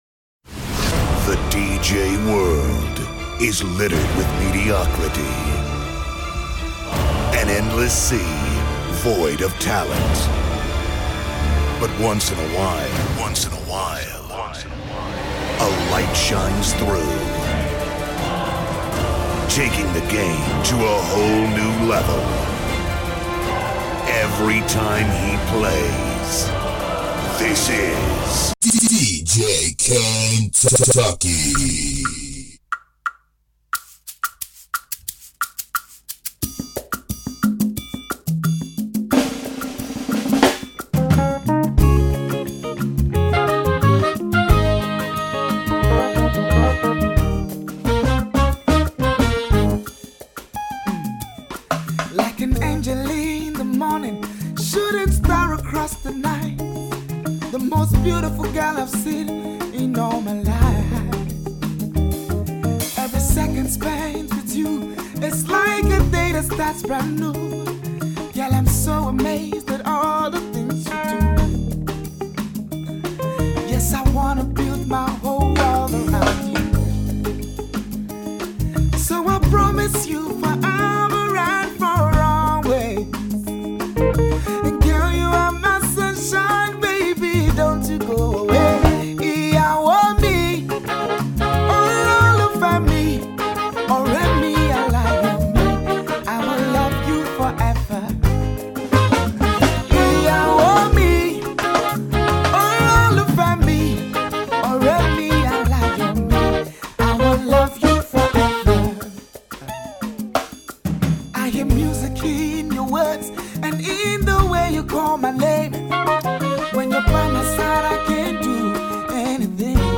This mix contains varieties of dope afrobeat love tunes.